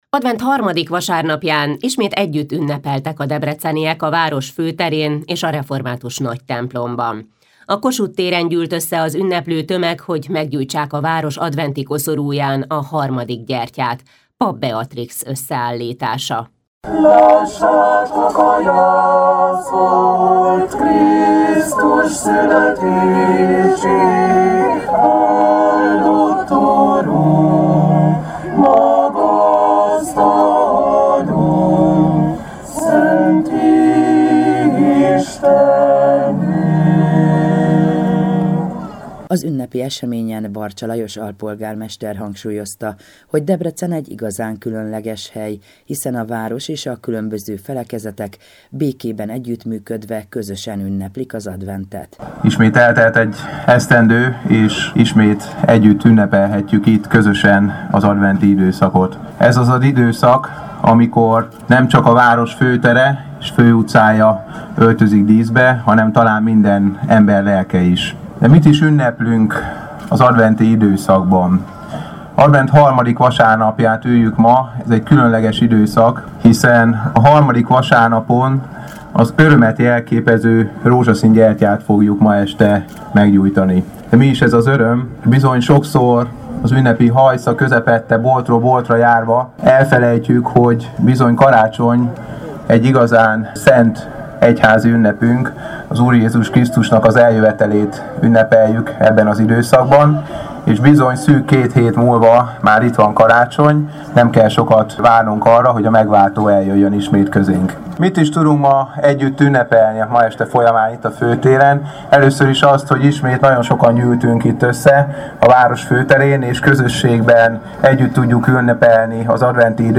Közreműködtek a Szent Háromság Magyar Orthodox Templom Kórusa, s fellobbant a harmadik adventi gyertyaláng.